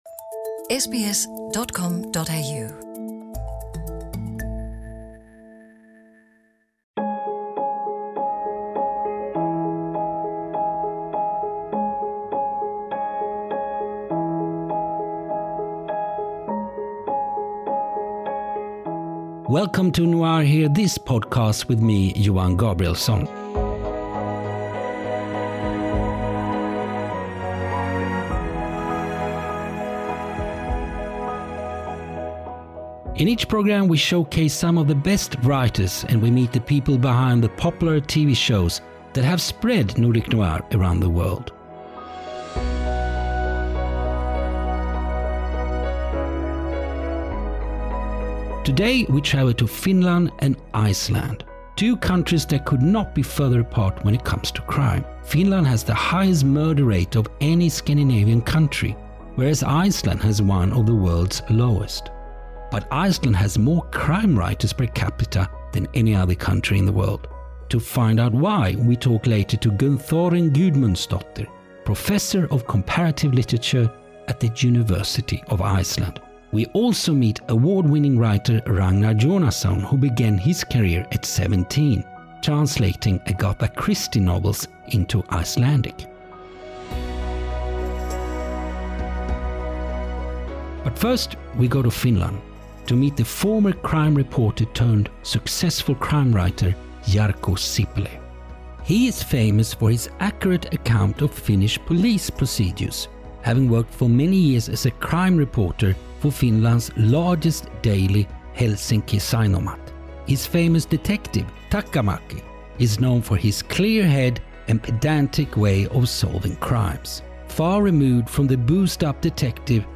This episode's featured book reading is of Ragnar Jónasson’s Snowblind (Orenda Books)